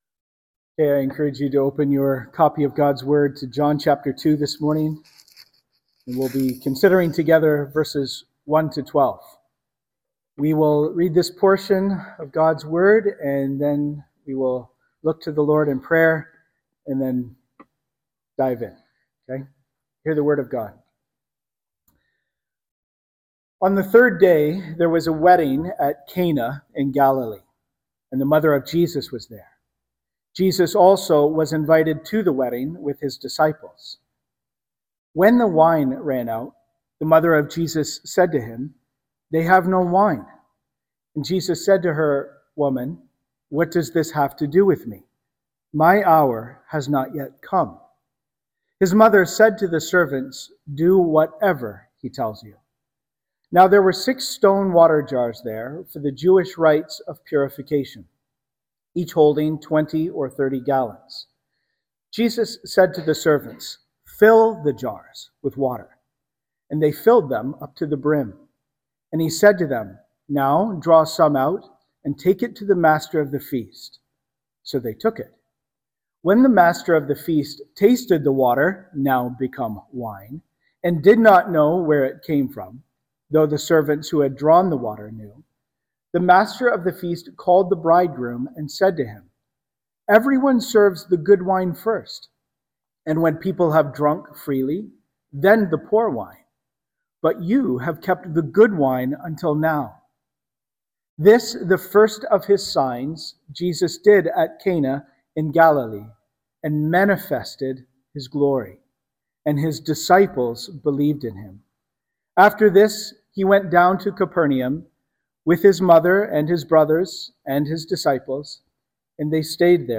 Sermons - Cannington Baptist Church